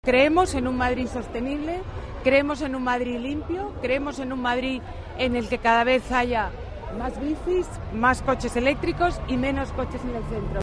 Nueva ventana:Declaraciones de Ana Botella: un Madrid con más bicis